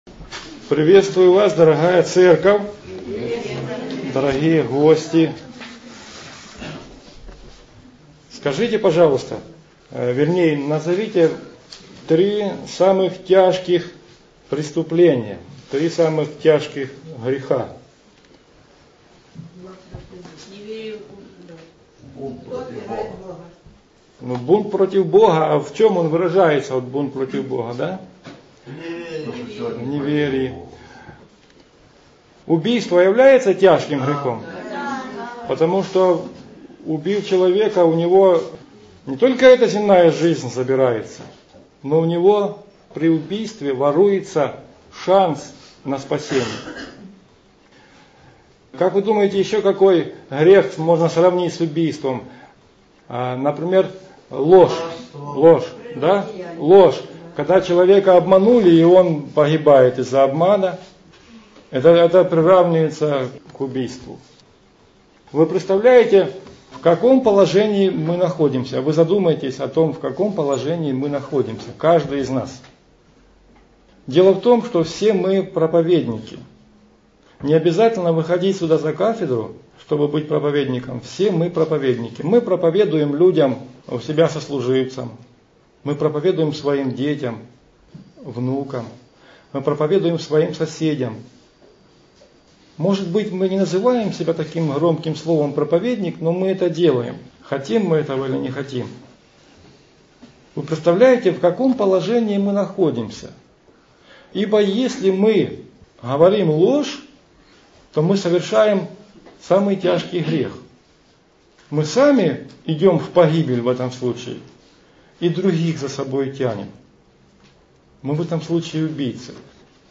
Аудио-проповедь